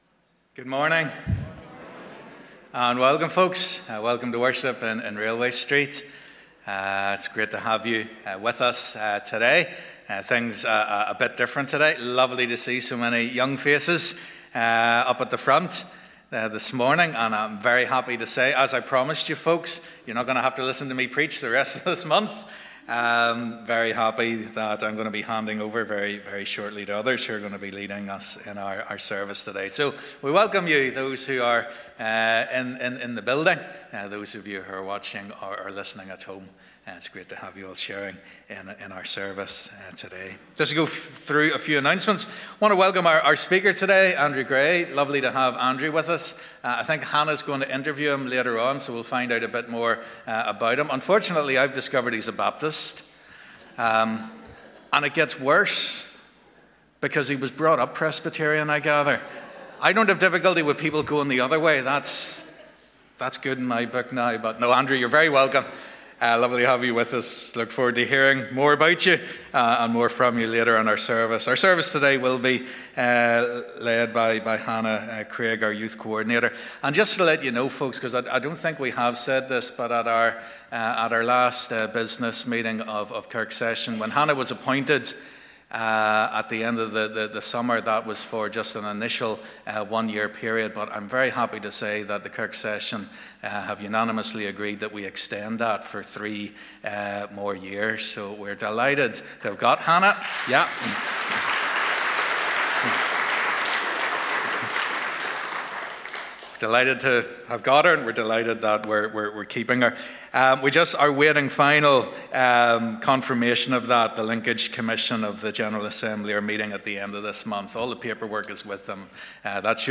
Today we join together for our Youth and Children's service to celebrate God's goodness to us over the last year.